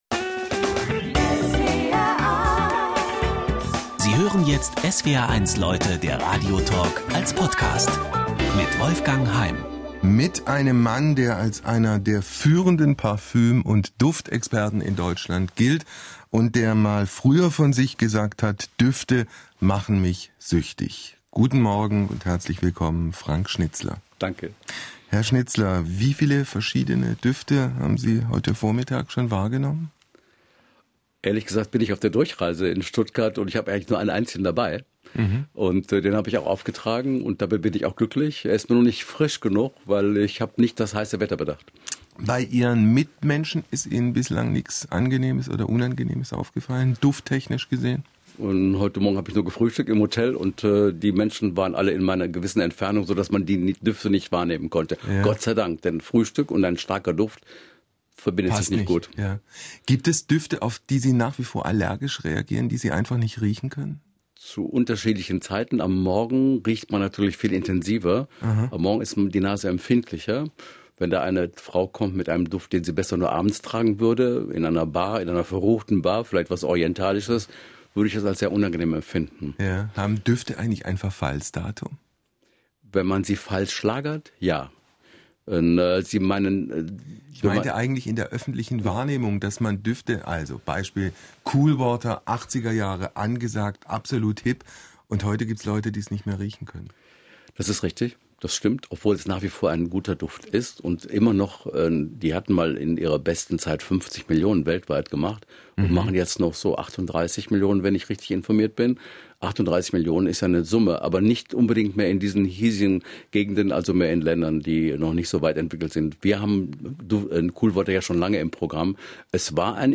Radio-Interview SWR1 „Gerüche für Genießer“ | Schnitzler Consulting Düsseldorf